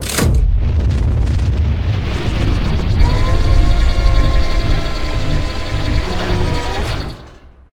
railgun.ogg